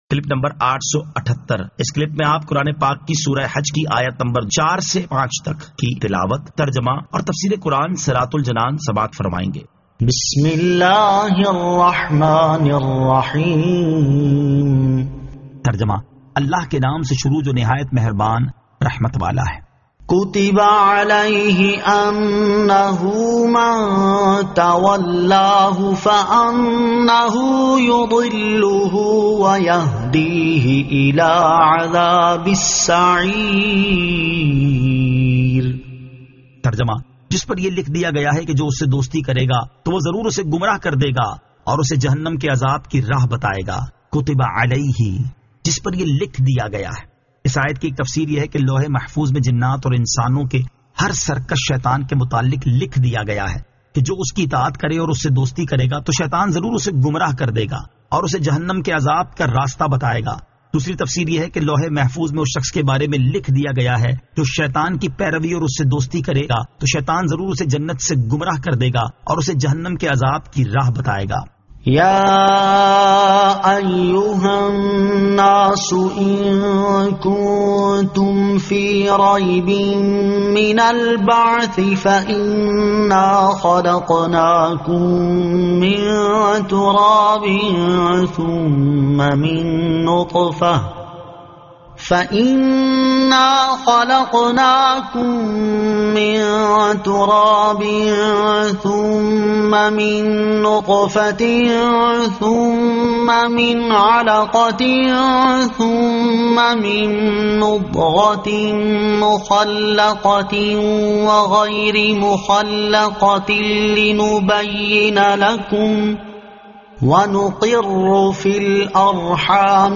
Surah Al-Hajj 04 To 05 Tilawat , Tarjama , Tafseer